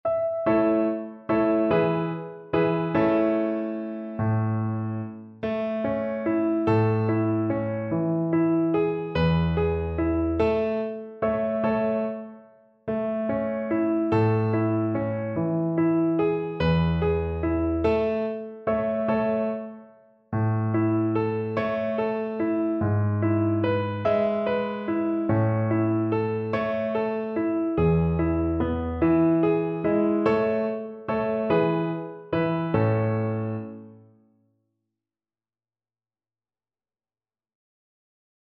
One in a bar =c.145
3/4 (View more 3/4 Music)